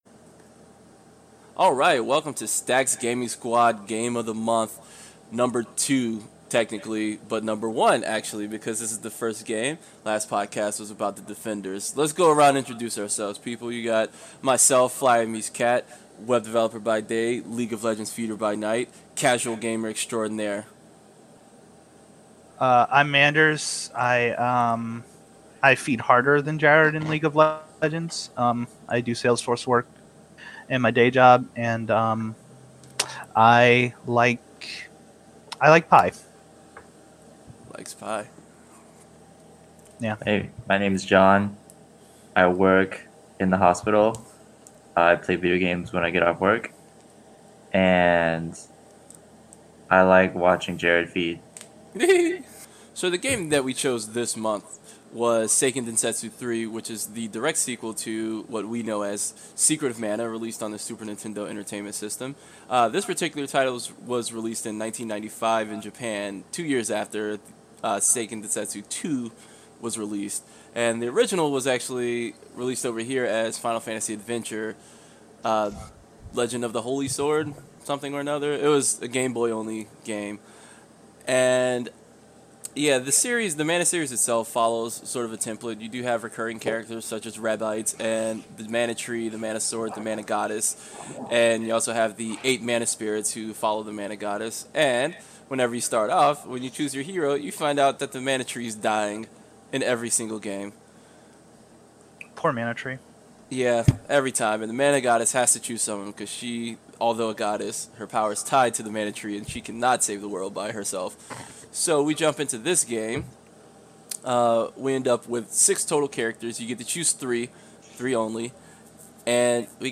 I won’t spoil too much but there is an outtake at the very end so listen all the way through.